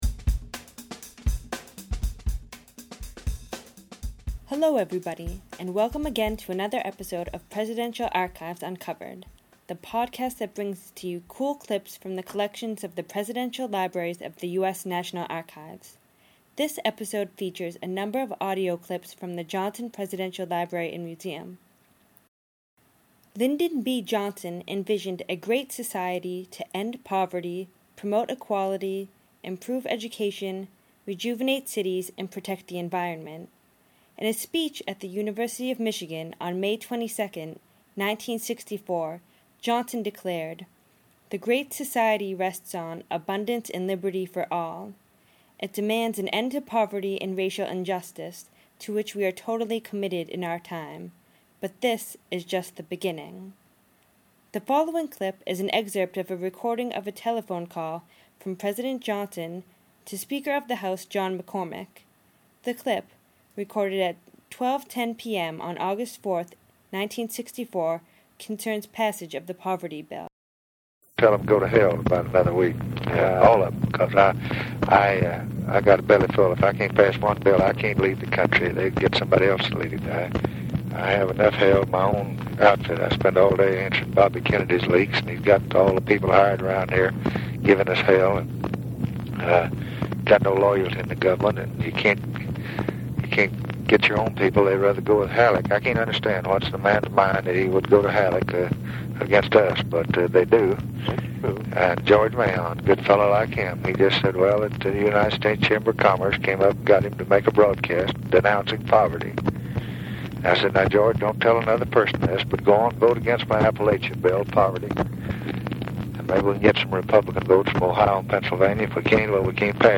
You'll hear the presidents speak both publicly and privately about issues of their day.